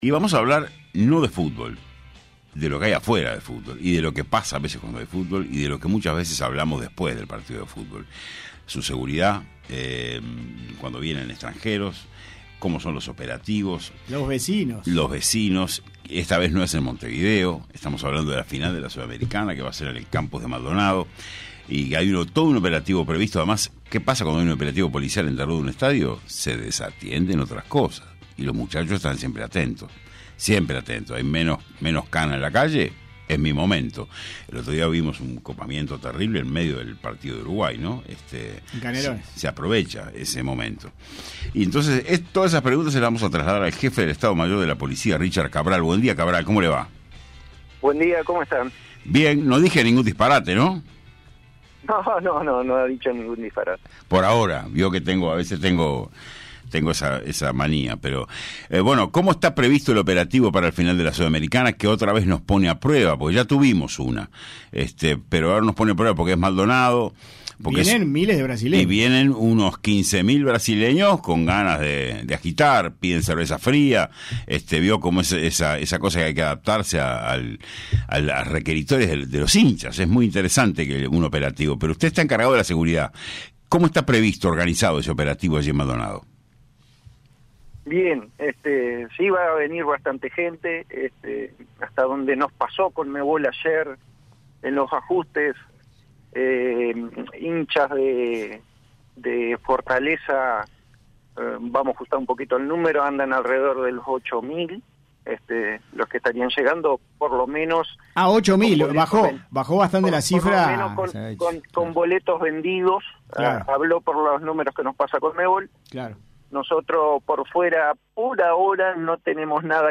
El Jefe del Estado Mayor de la Policía, Richard Cabral en entrevista con 970 Noticias dijo que el operativo policial para la final entre Fortaleza de Brasil y Liga de Quito de Ecuador en el Campus de Maldonado, comienza a las 6 horas del sábado 27 de octubre y participarán más de 800 efectivos policiales.